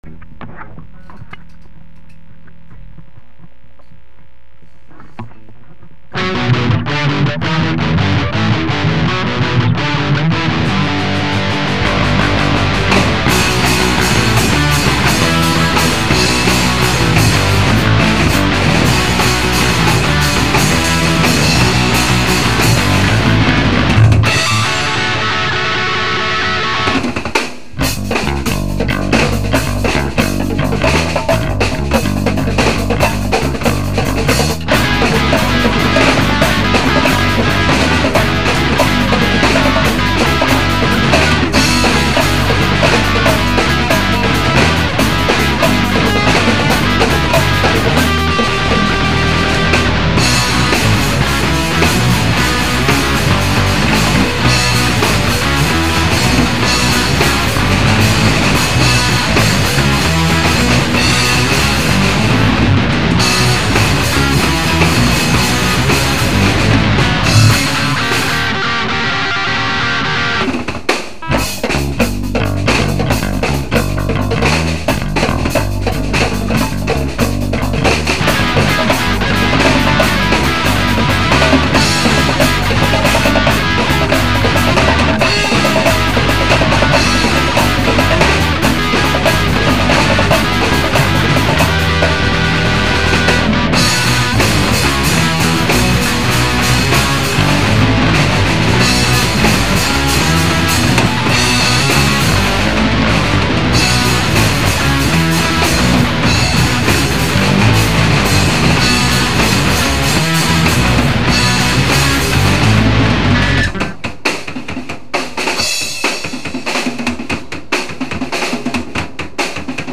MY EX BANDs DECENT RECORDING